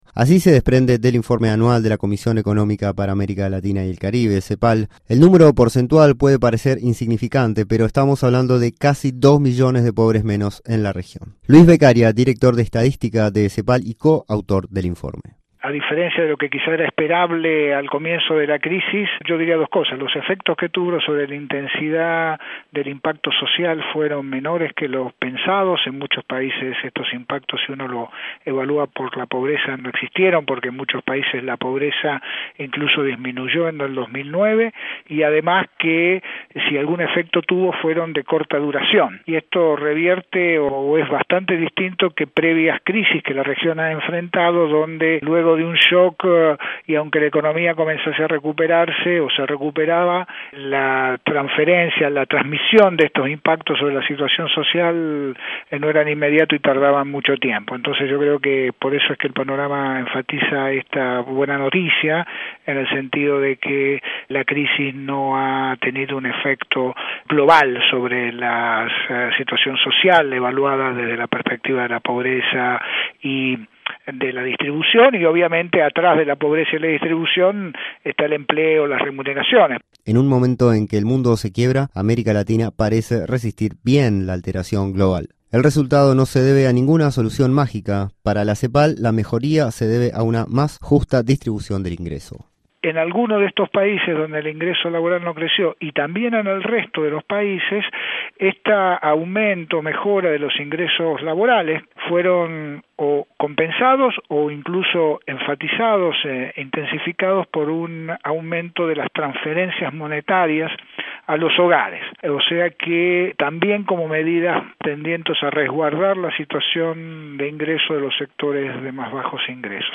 Habrá 1% menos de pobres a fines de año en comparación con igual período de 2009. Escuche el informe de Radio Francia Internacional.